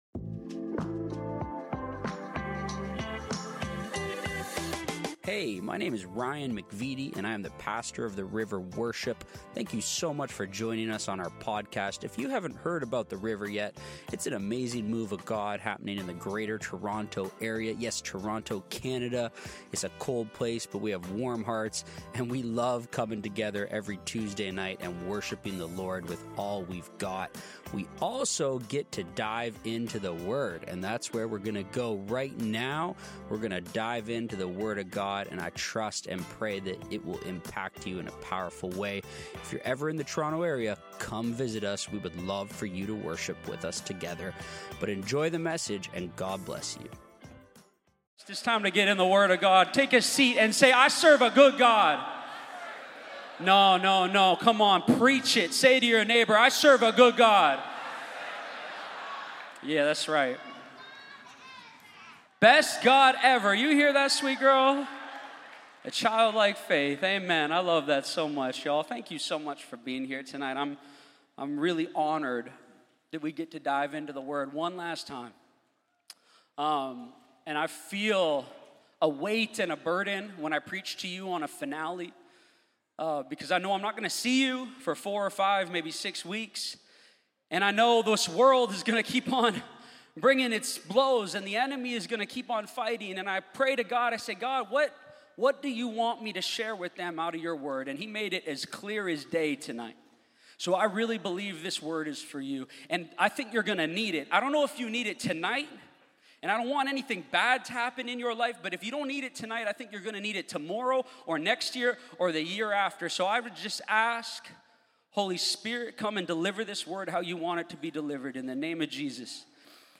Wind and Waves – The River Worship - Sermons – Podcast – Podtail